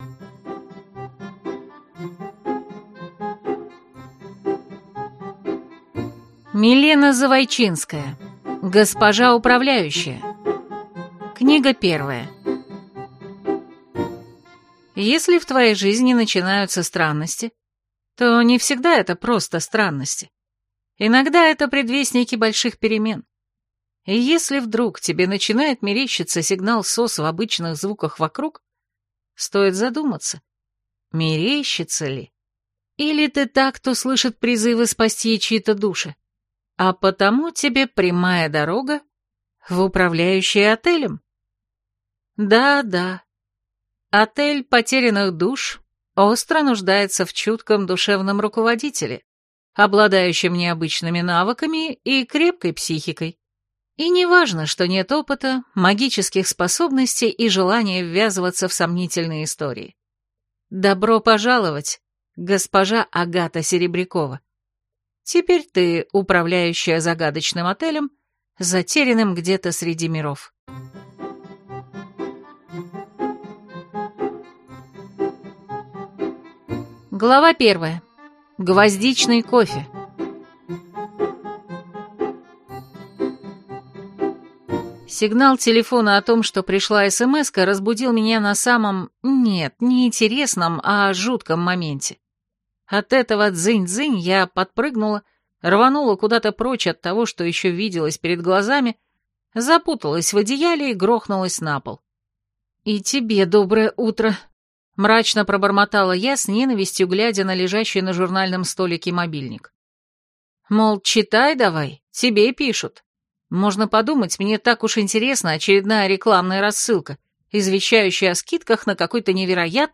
Аудиокнига Госпожа управляющая | Библиотека аудиокниг